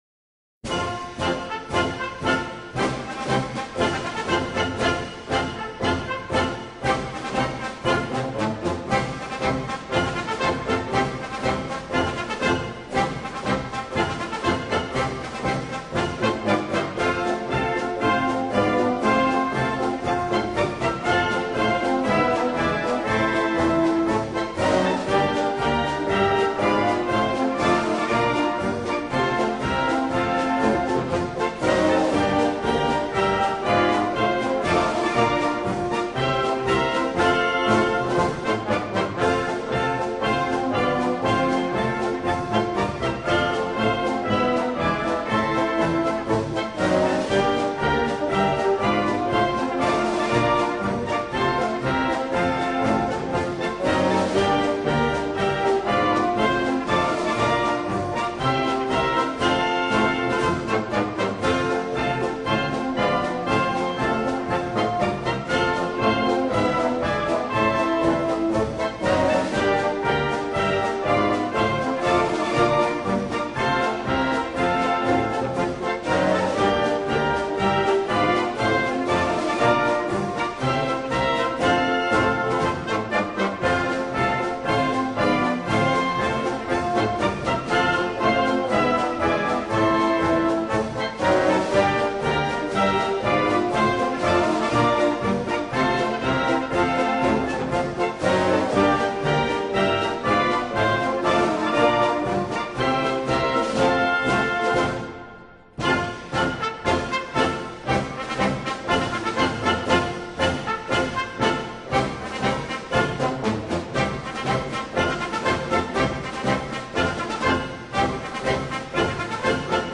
Марш на 4-та Преславска пехотна дивизия